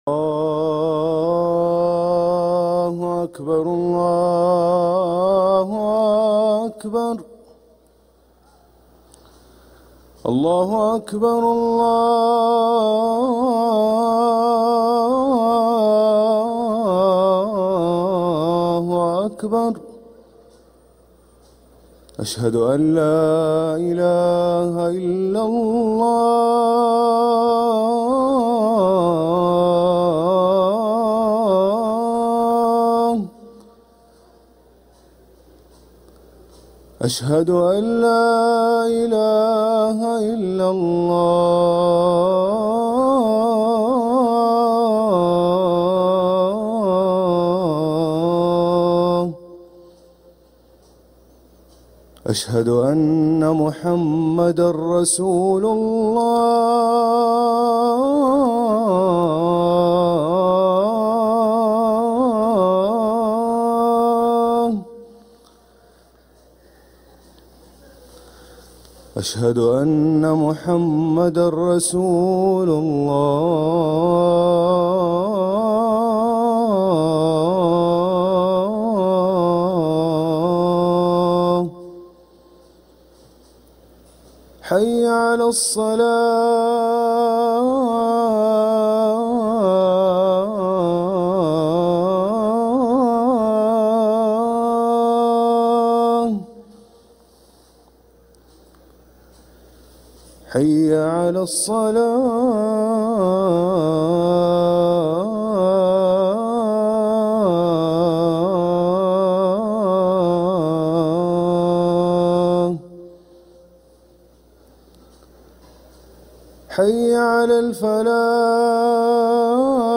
أذان الفجر للمؤذن هاشم السقاف الخميس 16 رجب 1446هـ > ١٤٤٦ 🕋 > ركن الأذان 🕋 > المزيد - تلاوات الحرمين